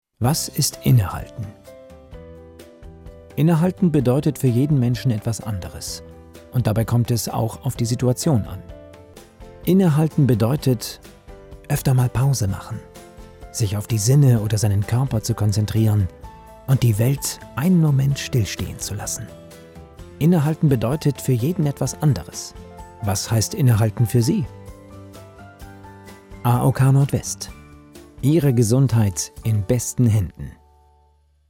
Deutscher Sprecher für Radio & Fernsehen Industriefilme, Werbung, Reportagen, Dokumentationen, Overvoice, Nachrichten, Trailer mittlere Tonlage, Referenzen u.a. ARD, RB, NDR, BMW, Arte, Merz Pharmaceuticals, Messe Bremen, Kunsthalle Bremen
Kein Dialekt
Sprechprobe: Werbung (Muttersprache):